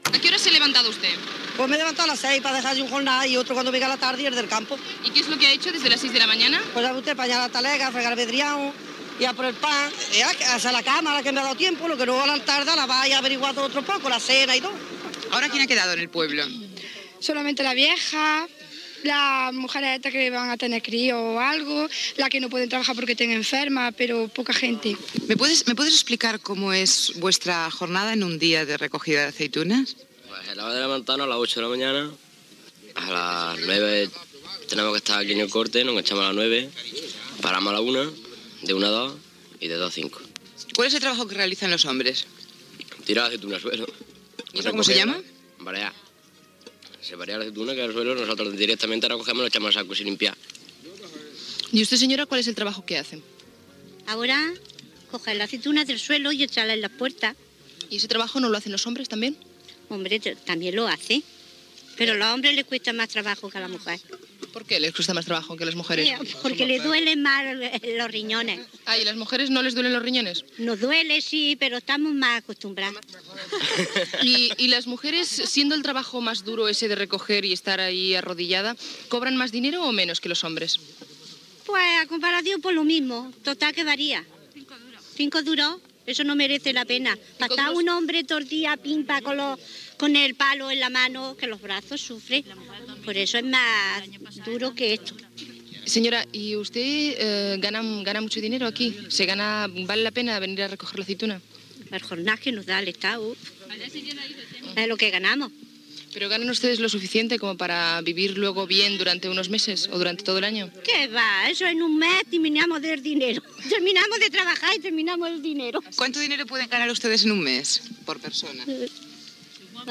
Fragment d'un reportatge sobre la recollida d'olives a Jaén
Info-entreteniment
Programa presentat per Luis del Olmo.